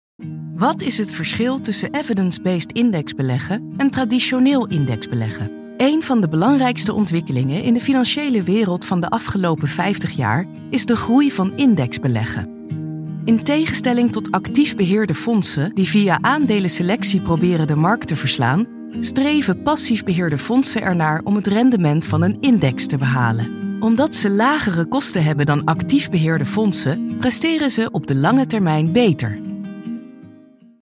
Narração
Sou uma locutora profissional holandesa com uma voz clara, calorosa e fresca.